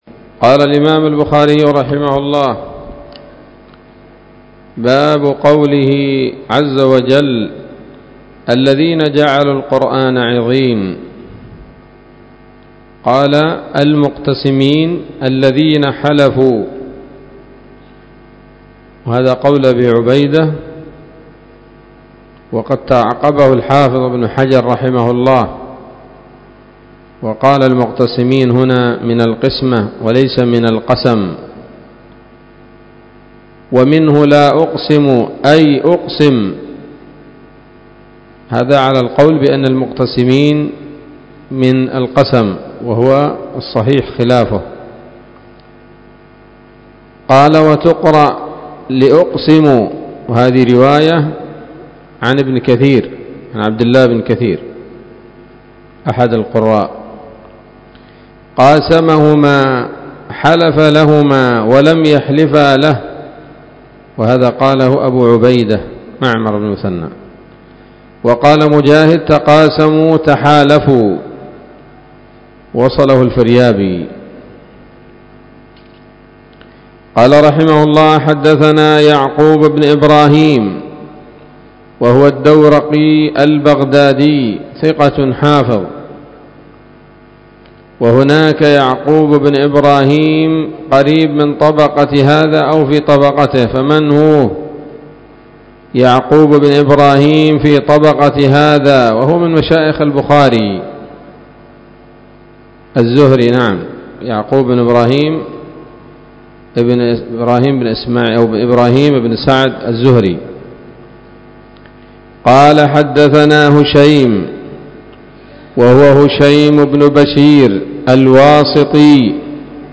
الدرس التاسع والأربعون بعد المائة من كتاب التفسير من صحيح الإمام البخاري